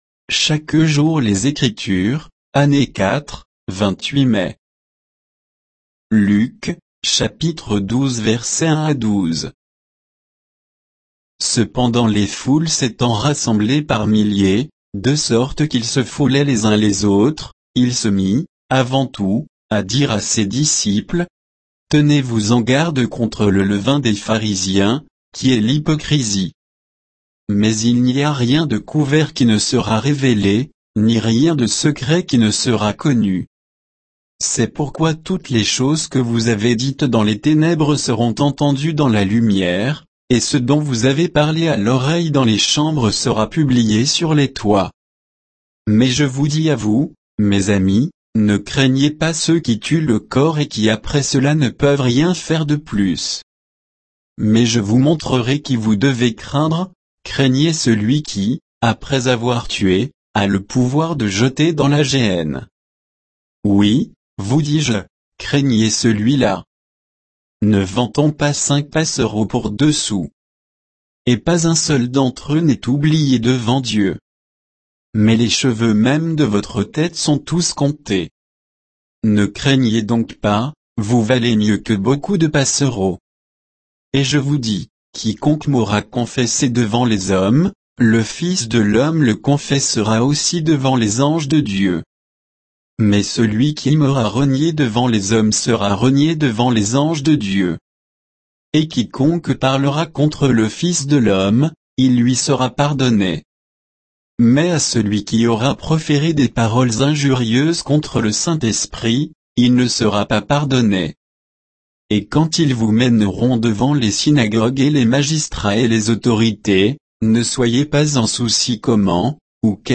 Méditation quoditienne de Chaque jour les Écritures sur Luc 12, 1 à 12